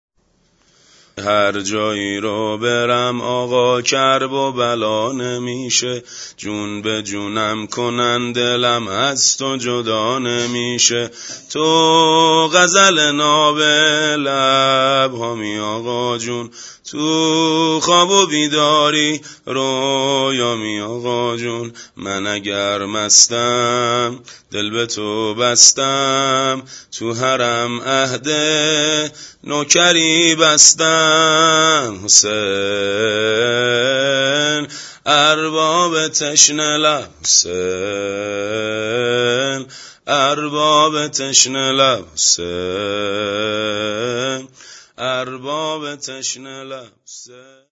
واحد شور